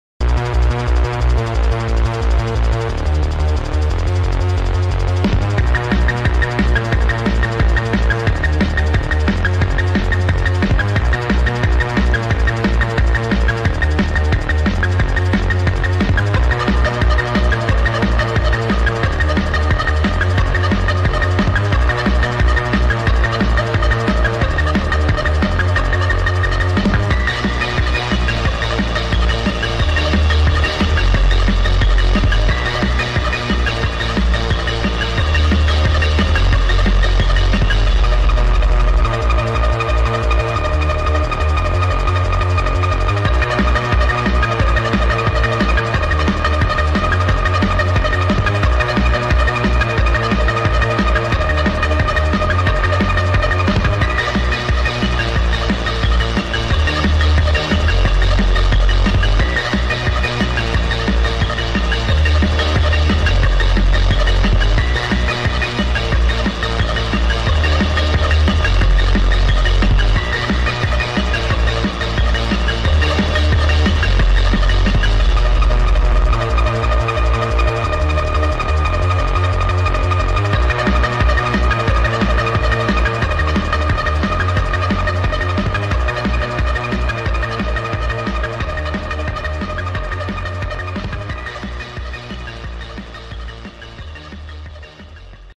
با ریتمی تند و قدرتمند با فضایی خاص و تاریک
فانک